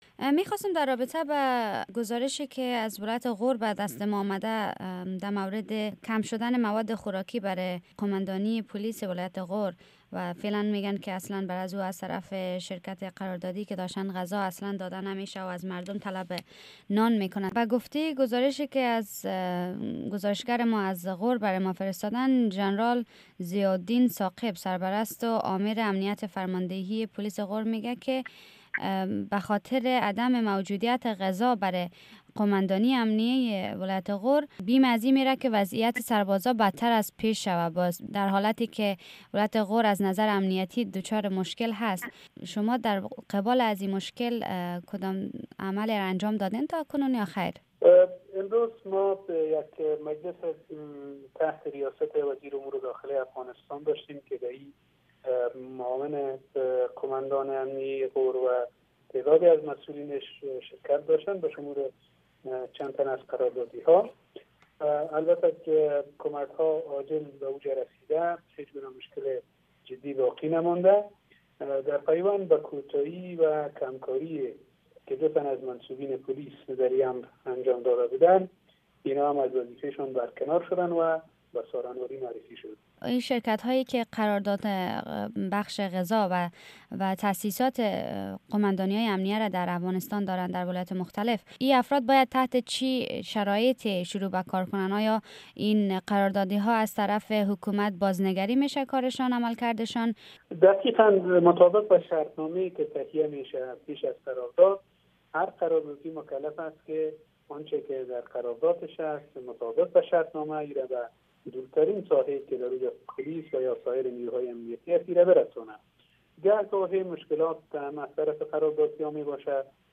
مصاحبۀ کامل